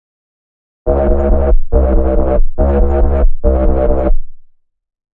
描述：140 bpm的dubstep低音。由自制的贝斯样本制成（标语有更多信息）。
Tag: 低音 回响贝斯 循环 摆动